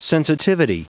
Prononciation du mot sensitivity en anglais (fichier audio)
Prononciation du mot : sensitivity